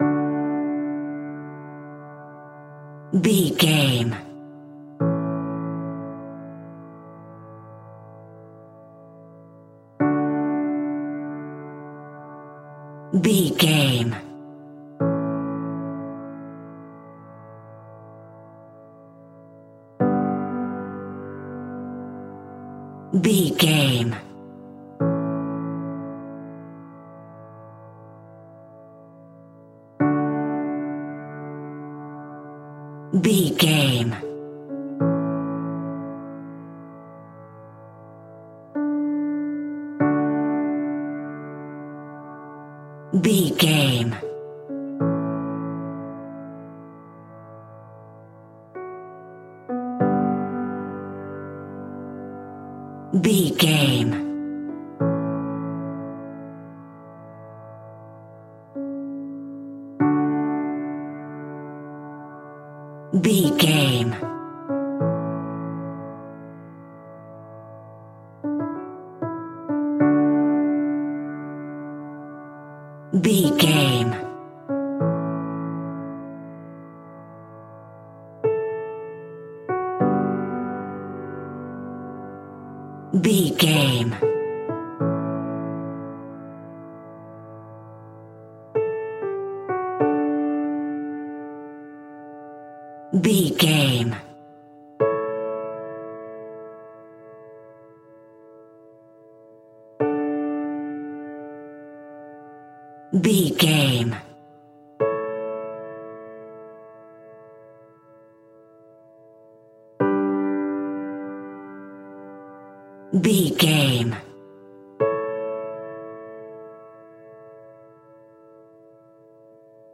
Ionian/Major
Slow
relaxed
tranquil
synthesiser
drum machine